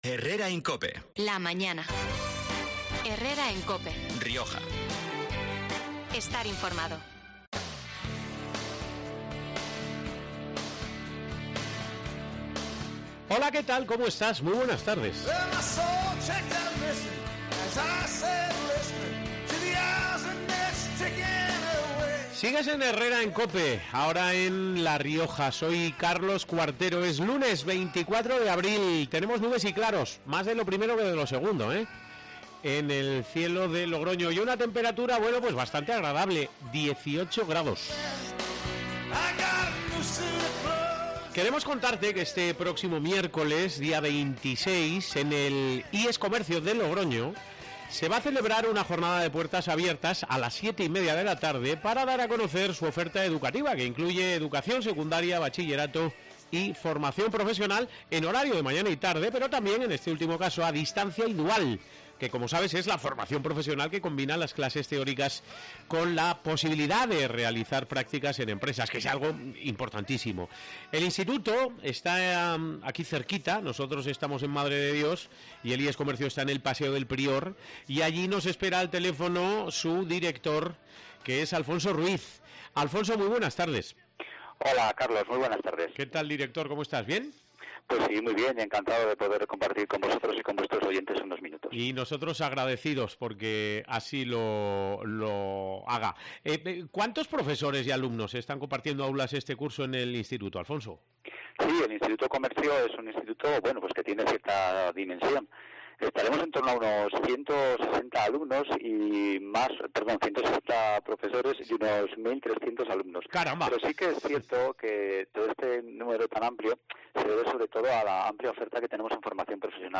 por teléfono